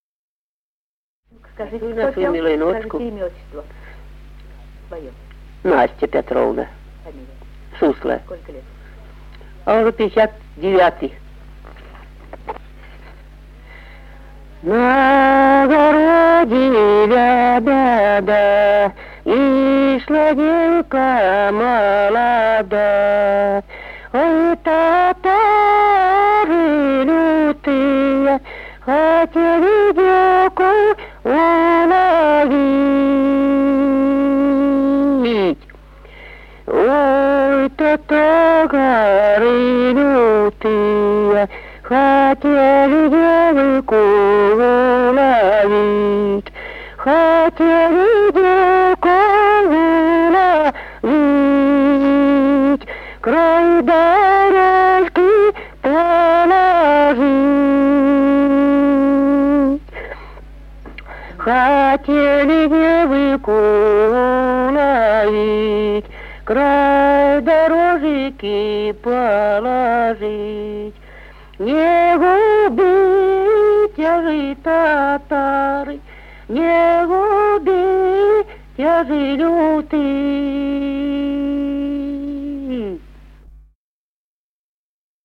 Песни села Остроглядово в записях 1950-х годов